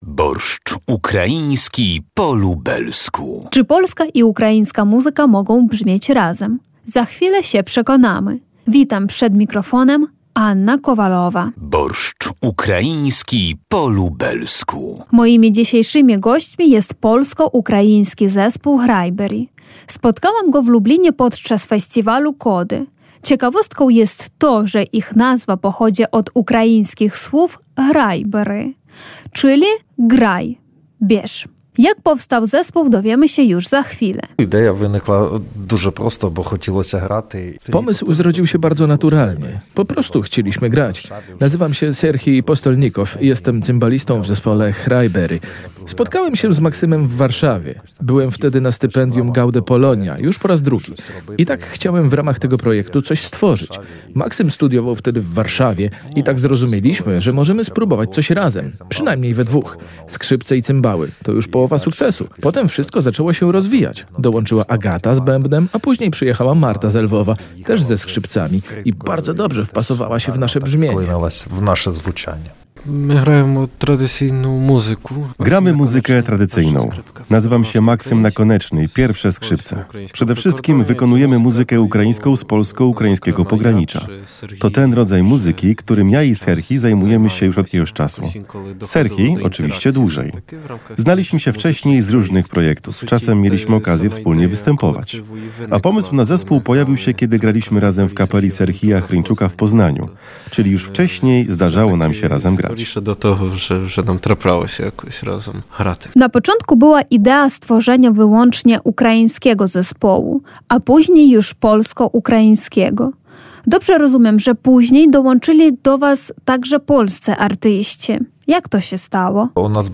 Czy polska i ukraińska muzyka mogą brzmieć razem? Za chwilę się przekonamy.